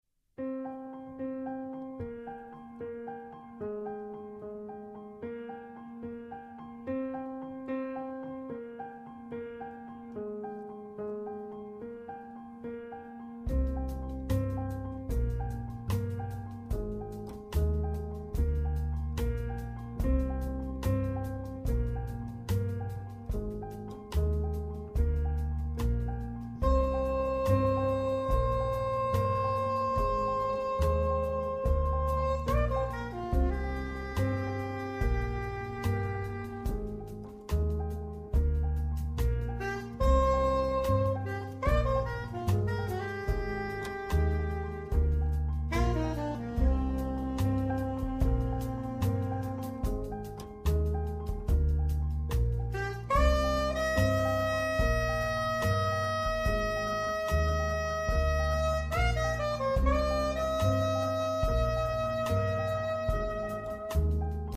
sax tenore e soprano
pianoforte
contrabbasso
batteria